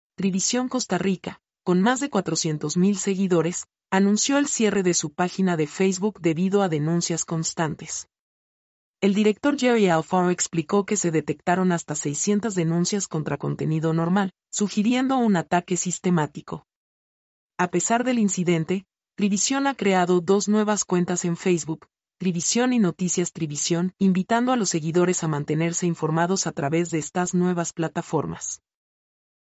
mp3-output-ttsfreedotcom-49-1.mp3